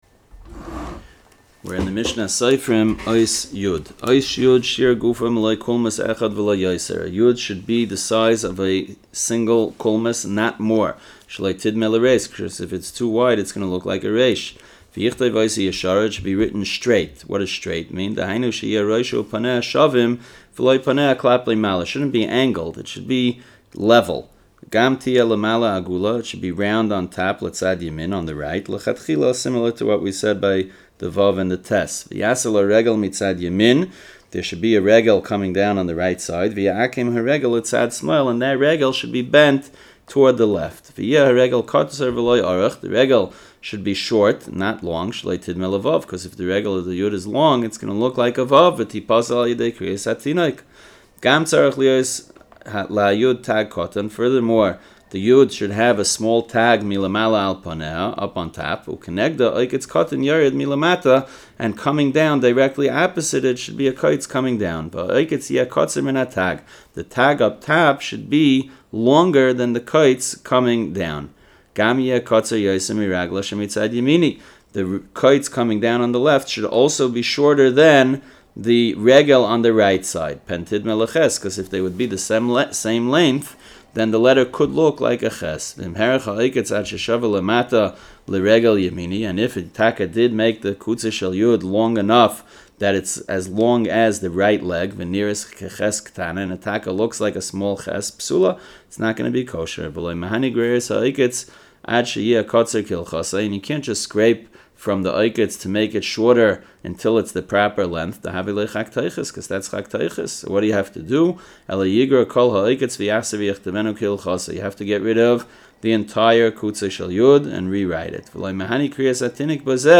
Audio Shiurim - The STa"M Project | Kosher-Certified Mezuzos, Tefillin & STa”M